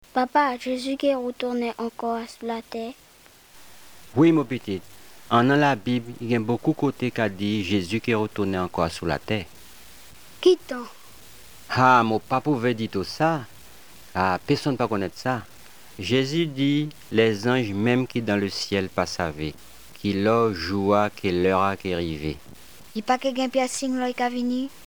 Here are recordings of a number of different people speaking varieties of French, or French-based Creoles.